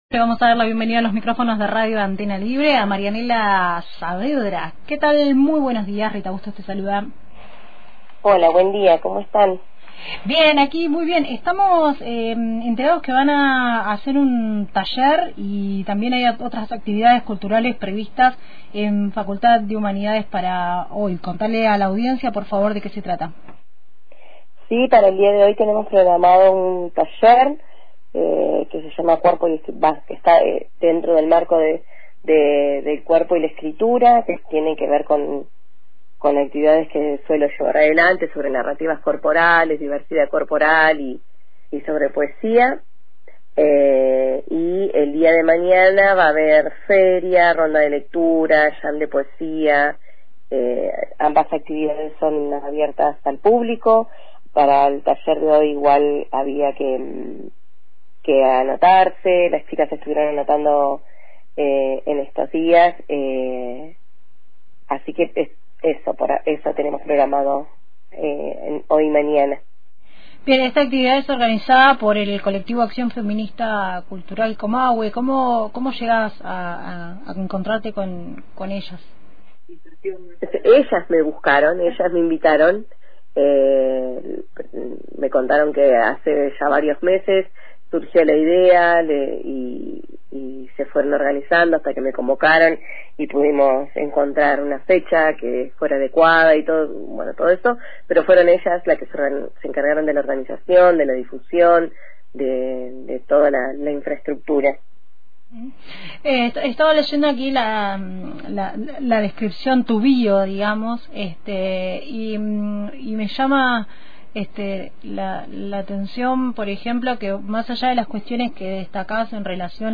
Para conocer más sobre esta propuesta dialogamos